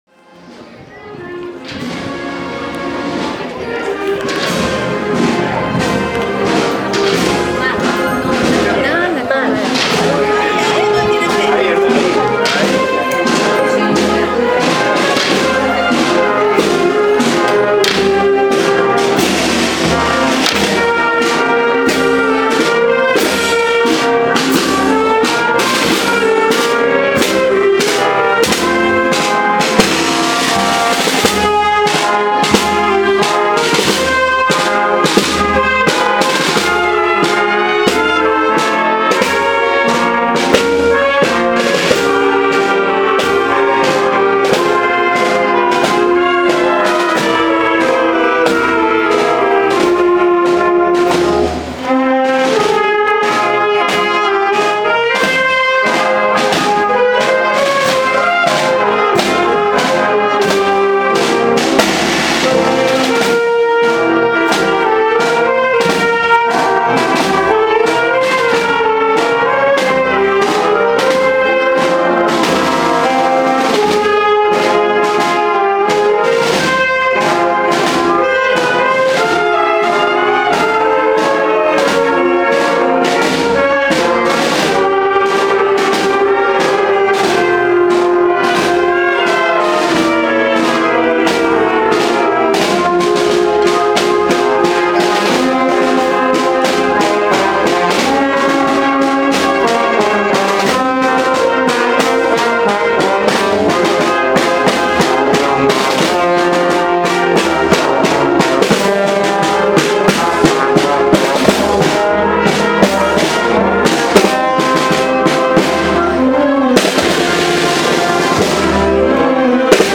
Procesión de las Tres Avemarías 2014
El último Domingo de mayo recorrió las calles de Totana la procesión de las Tres Avemarías.
Numerosos vecinos tomaron parte en ella, así como niños ataviados con sus trajes de Primera Comunión, que acompañaron el trono de las Tres Avemarías. También participó la Banda de la Agrupación Musical de Totana.